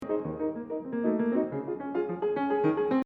Do you hear those little notes coming back again in the middle of all the jumping around?